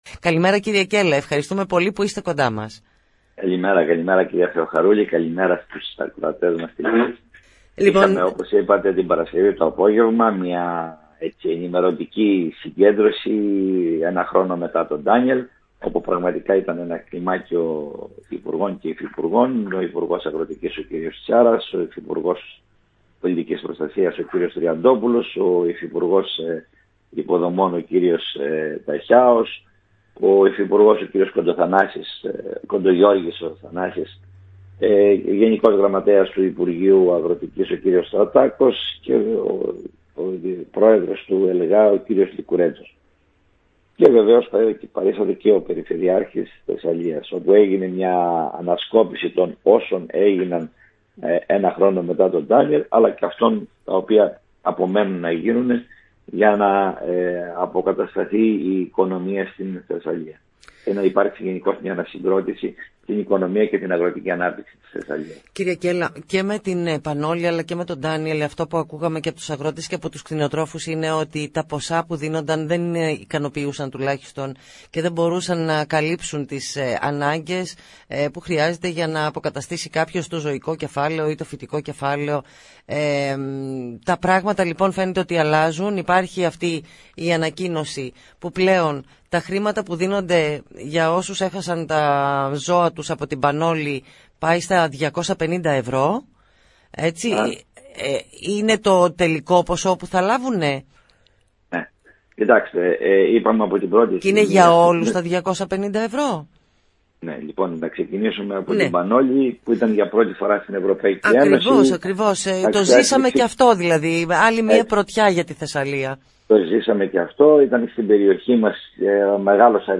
σε συνέντευξη που έδωσε στην ΕΡΤ Λάρισας
sinenteuxi-kellas-ert-larissas.mp3